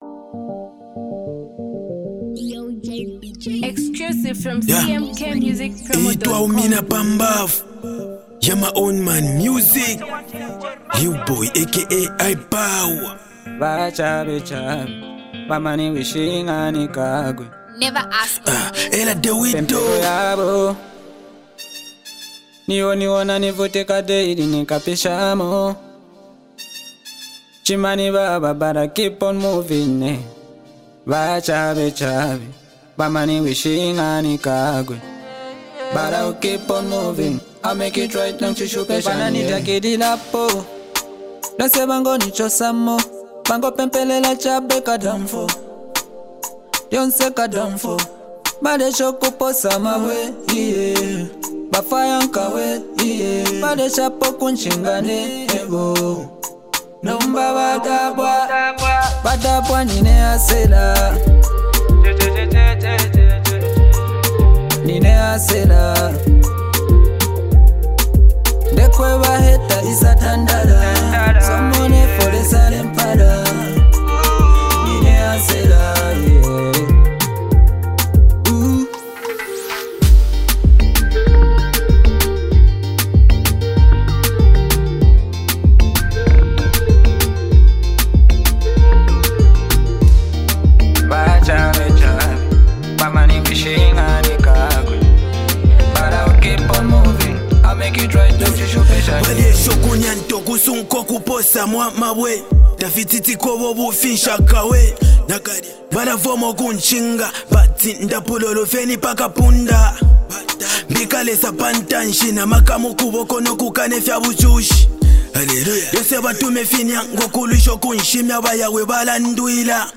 soul-stirring anthem
emotive vocals